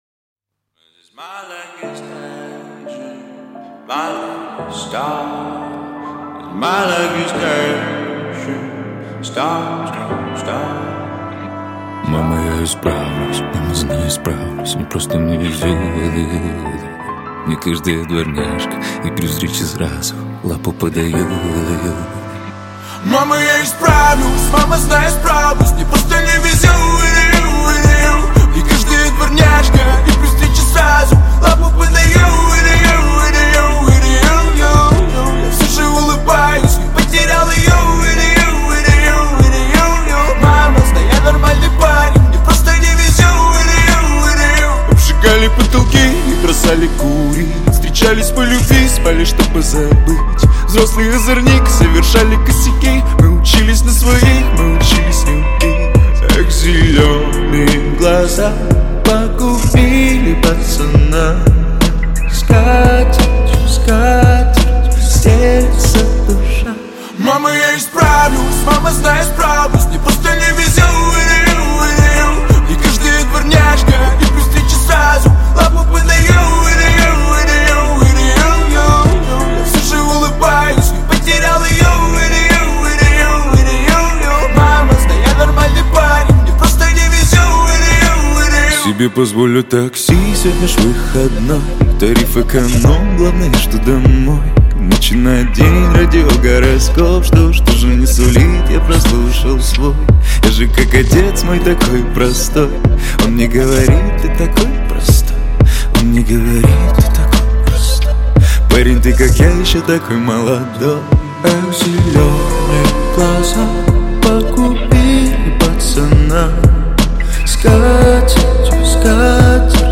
Хип-хоп
Жанр: Хип-хоп / Русский рэп